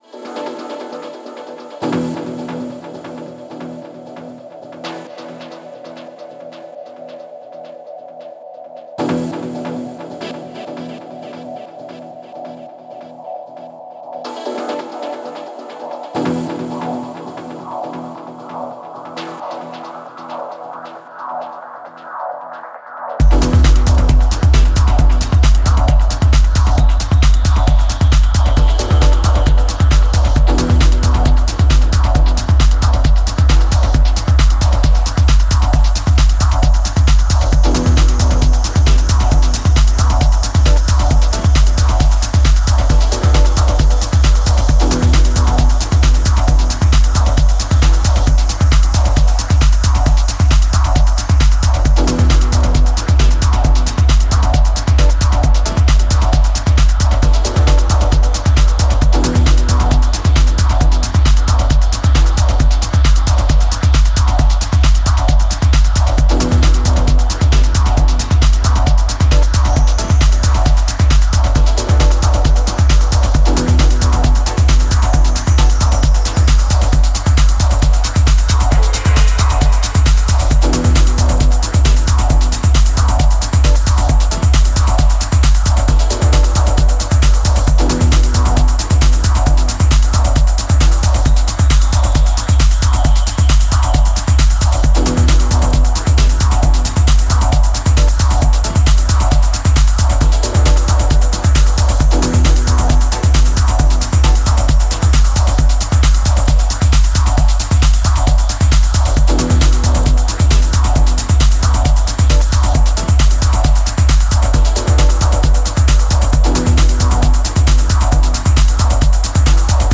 ブレイクビーツ導入も疾走感に拍車を掛ける、清涼感のあるダビーなパッドが揺蕩うプロッギー・ミニマル・トランス